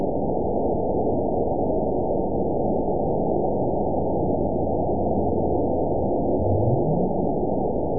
event 920474 date 03/27/24 time 05:11:22 GMT (1 year, 1 month ago) score 9.55 location TSS-AB02 detected by nrw target species NRW annotations +NRW Spectrogram: Frequency (kHz) vs. Time (s) audio not available .wav